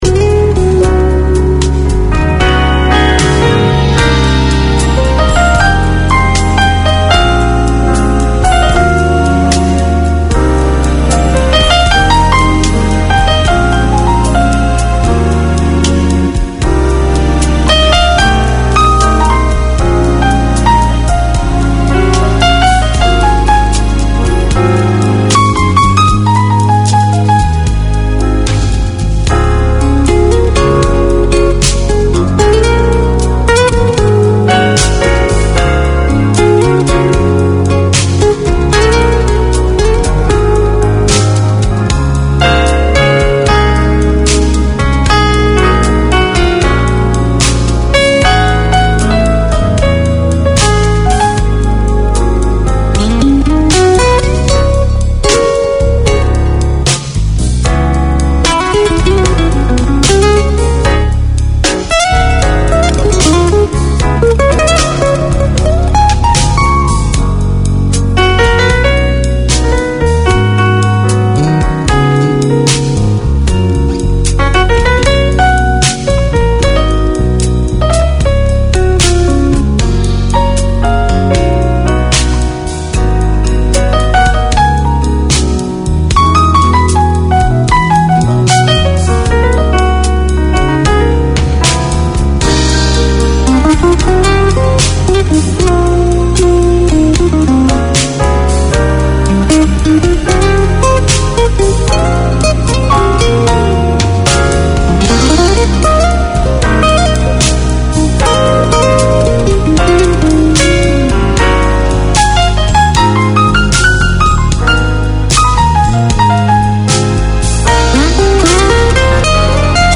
It is both a reflection of the Khmer community in Auckland and an avenue for new Cambodian migrants. Music mixes with news local and global, interviews, religious topics, settlement topics and issues, with talkback.